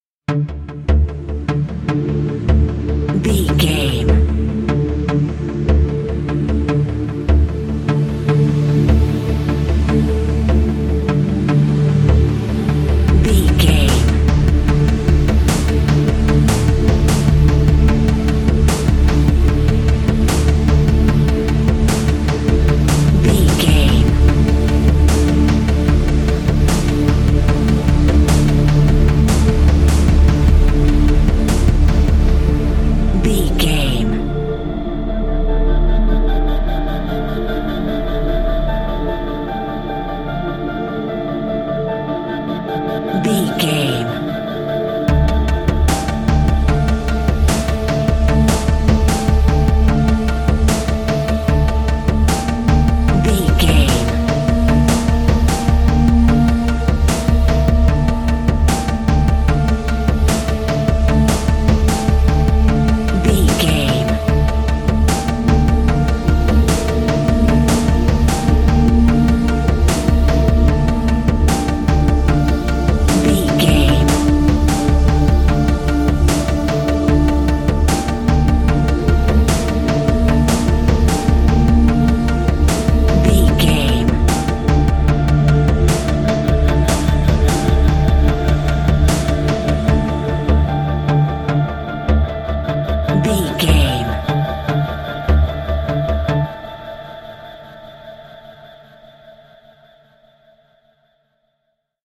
Thriller
Aeolian/Minor
D
Slow
drum machine
synthesiser
electric piano